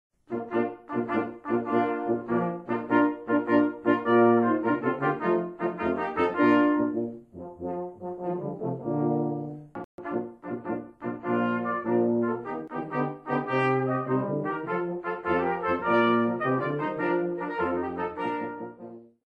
Einstimmiger Chorgesang zu 86 deutschen Volksliedern.
Probenmitschnitt